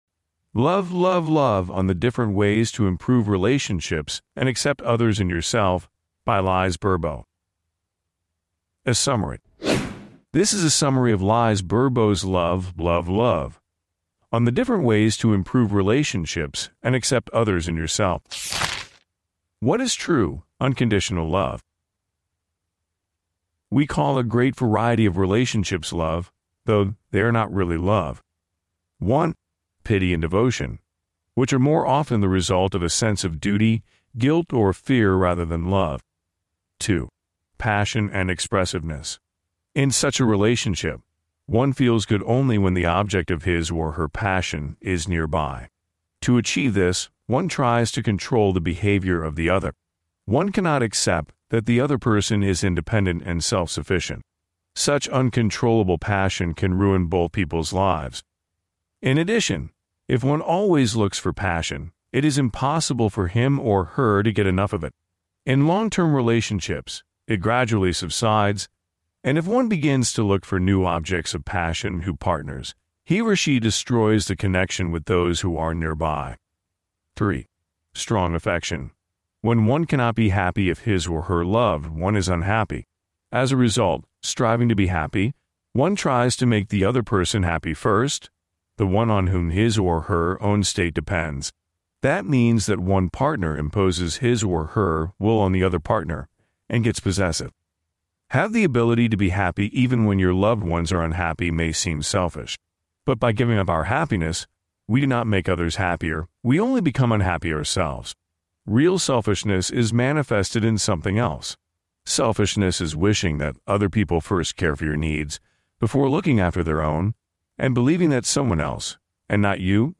Аудиокнига Summary: Amour – Amour – Amour. La puissance de l’acceptation. Lise Bourbeau | Библиотека аудиокниг